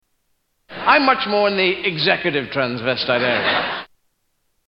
Tags: Comedians Eddie Izzard Eddie Izzard Soundboard Eddie Izzard Clips Stand-up Comedian